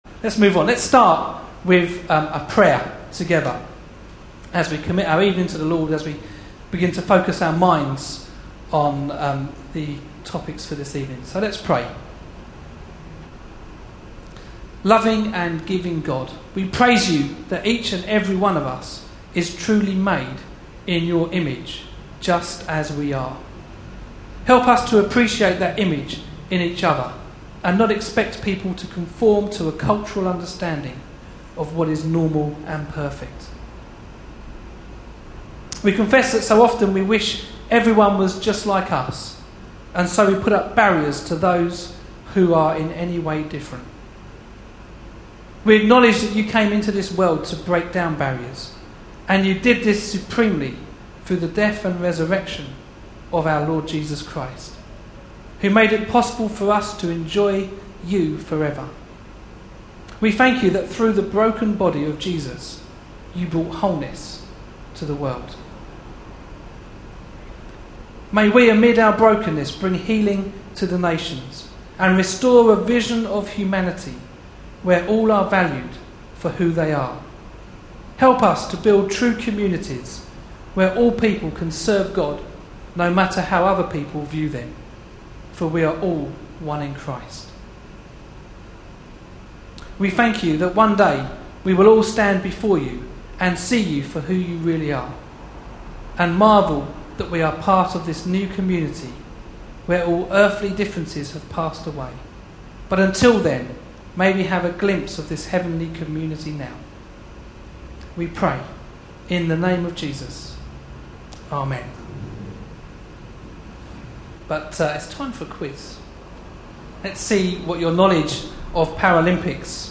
A sermon preached on 26th August, 2012, as part of our Olympic Ideals series.
Luke 14:15-23 Listen online Details Reading is Luke 14:15-23, preceded by a paralympic quiz (about 5 minutes). The service took place as the 2012 London Paralympic Games were starting.